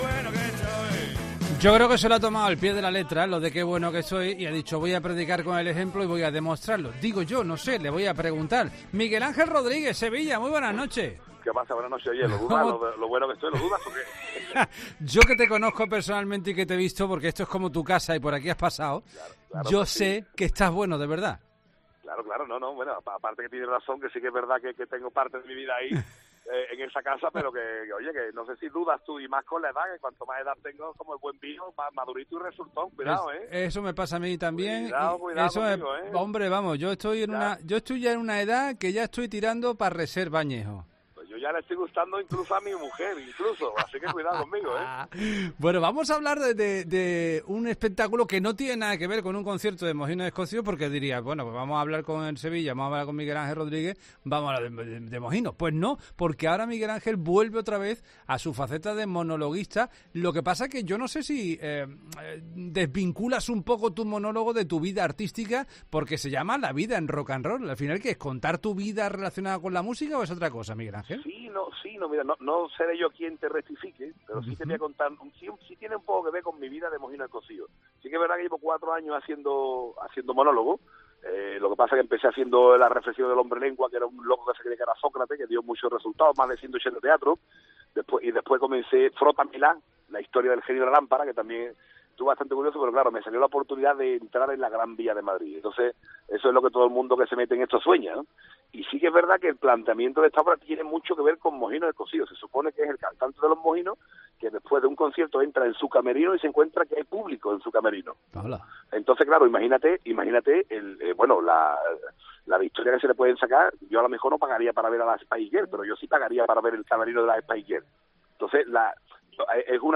El líder y cantante del grupo Mojinos Escozíos, Miguel Ángel Rodríguez 'El Sevilla', contagia su humor en 'La Noche de COPE' y con su nuevo espectáculo en Madrid 'La vida en rocanrol'.